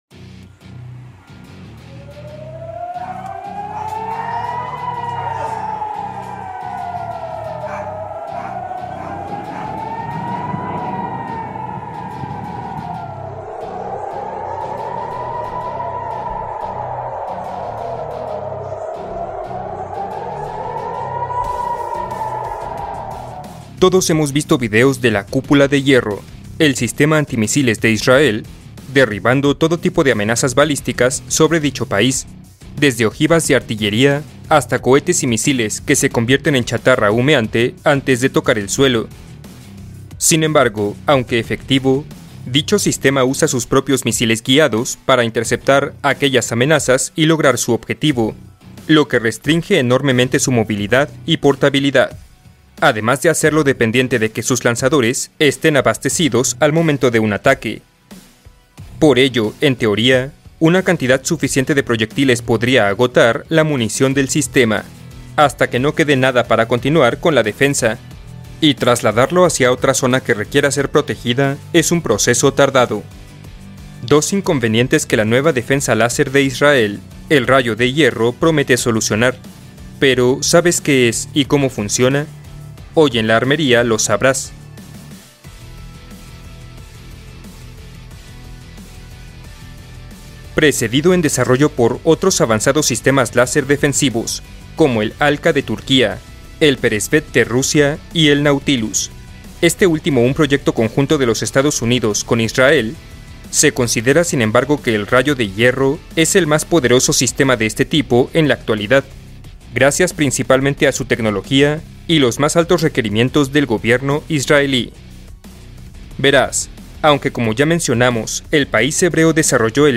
Relato de guerra